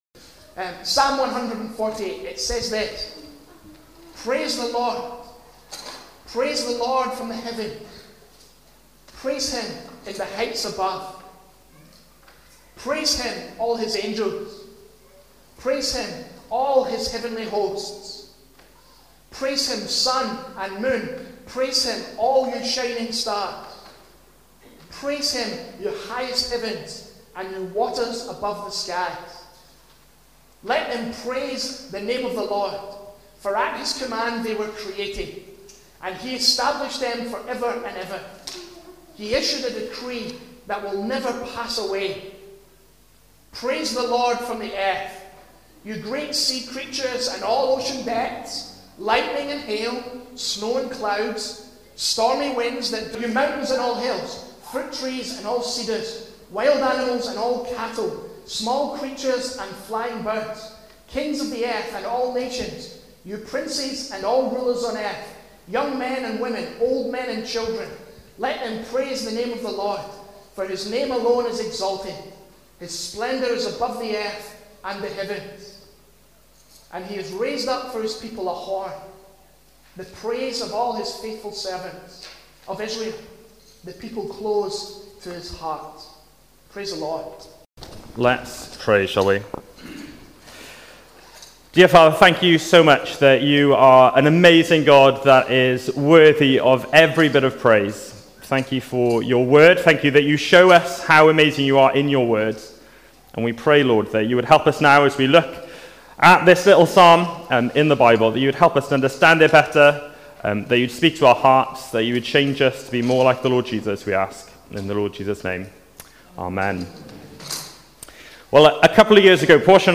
(Apologies for the poor audio)